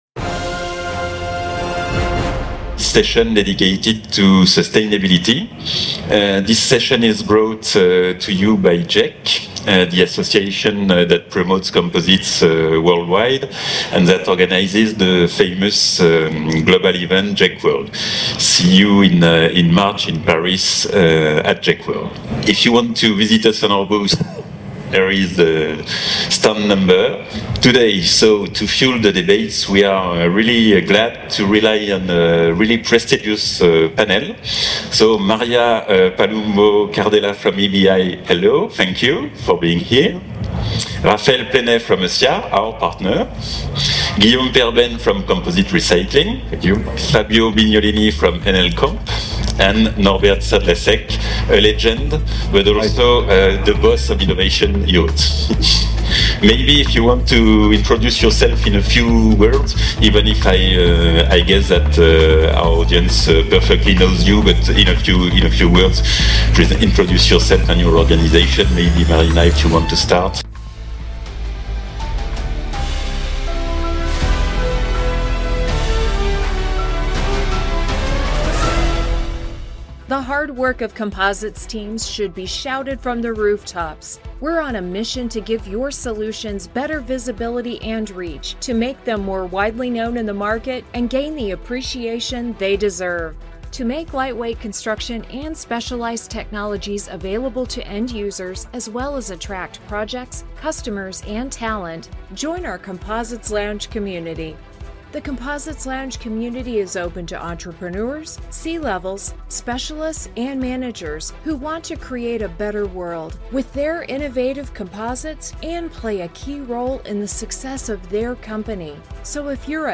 This isn’t just another panel—it’s a front-row seat to the future of composites.